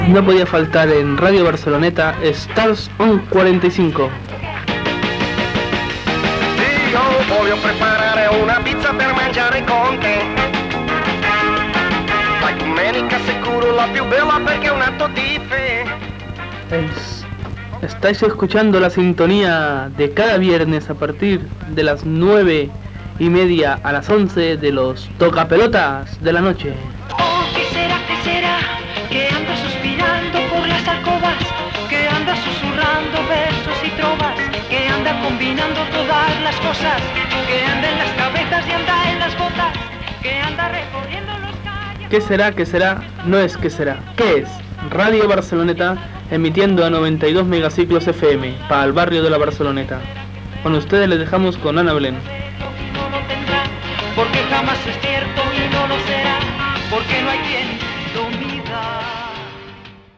1655b2c863bf3904a88059fad0dd1e1482ea2af8.mp3 Títol Ràdio Barceloneta Emissora Ràdio Barceloneta Titularitat Tercer sector Tercer sector Lliure Nom programa Stars on 45 Descripció Identificació i presentació d'un tema musical. Gènere radiofònic Musical